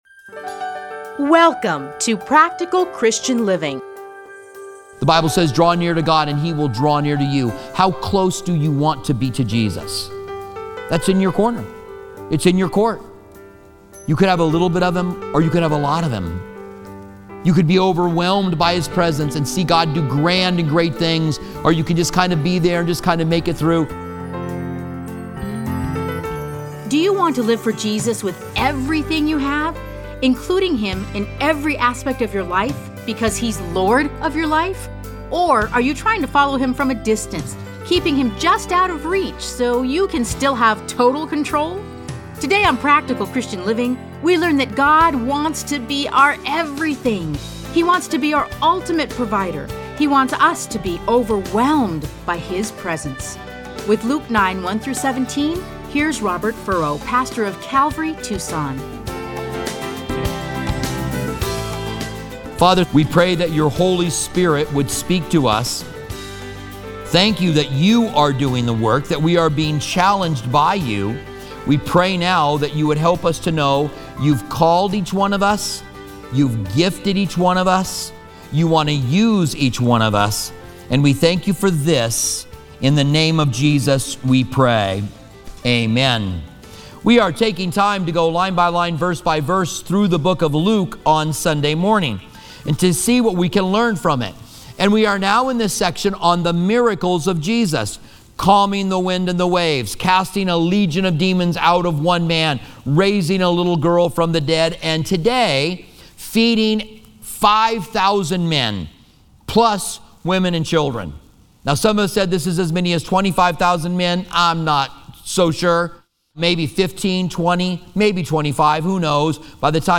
Listen to a teaching from Luke 9:1-17.